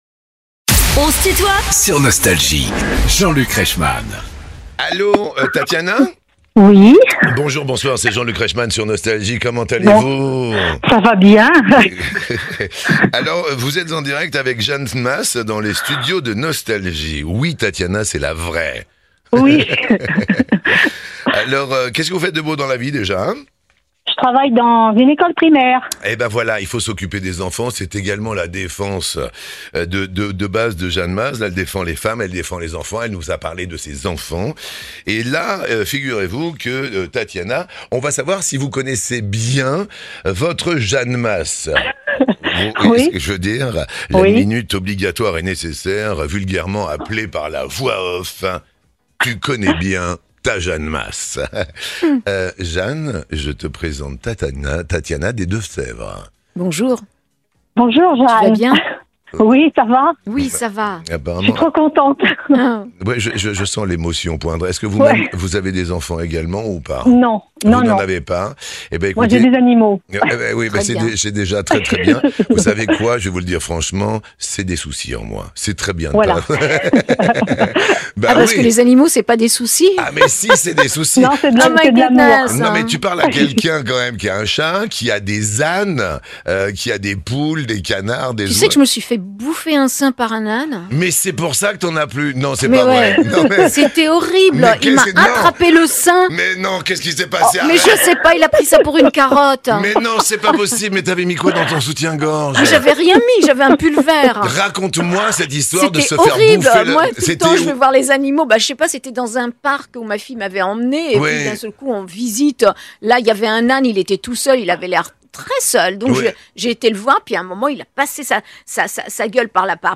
Les interviews
Invitée de Jean-Luc Reichmann dans "On se tutoie ?...", Jeanne Mas